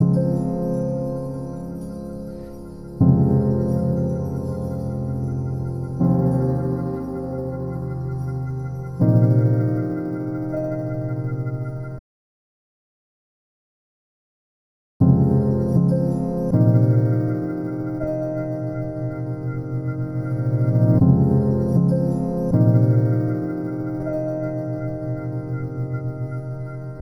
Chopping and Reversing
Start off by cutting the loop on each beat or half-beat and re-arranging the order to get a new melody.
In addition, you can also reverse some of the slices, which can lead to some interesting dynamics and movement (but don’t overdo it!).
Chopping-and-reversing.wav